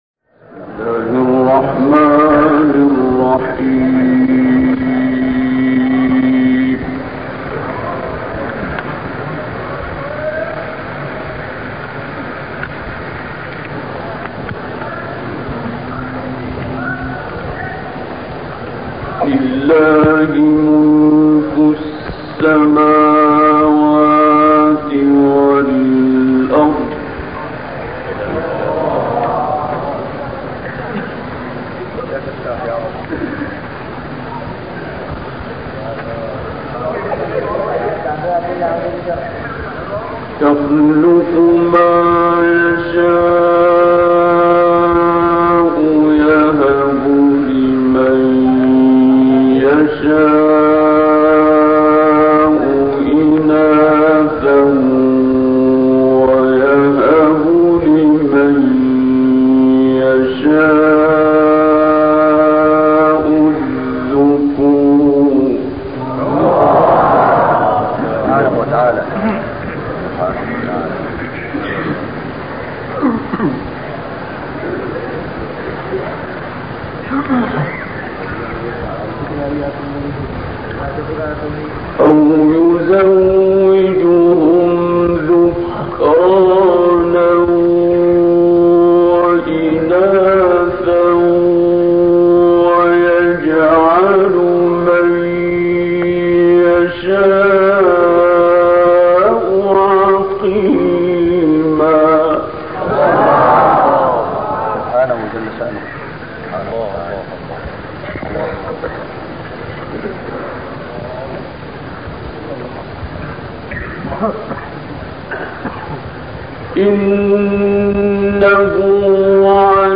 تلاوت سوره‌های مبارکه شوری و الحاقه اجرا شده در کشور پاکستان را با صدای استاد عبدالباسط می‌شنوید.